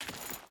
Dirt Chain Walk 4.ogg